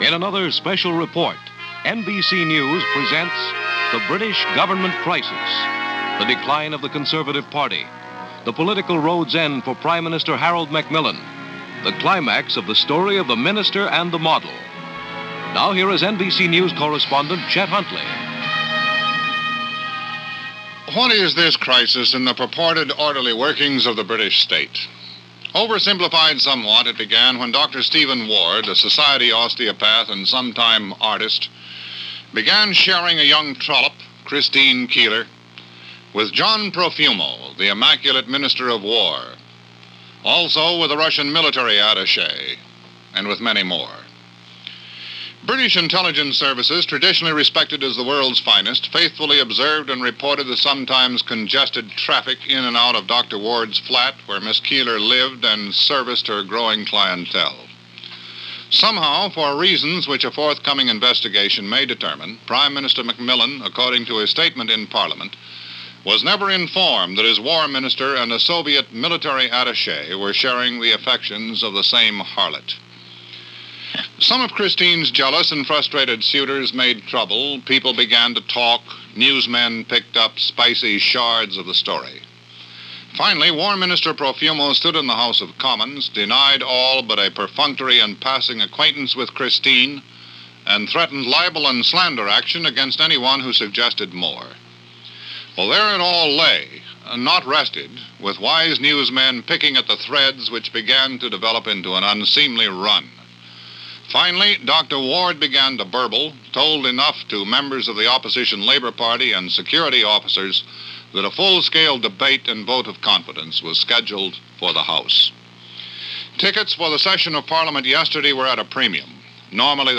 June 1963 - John Profumo-Christine Keeler - Anatomy Of A Scandal - Toppling of a government - NBC News Special on the Downing Street scandal.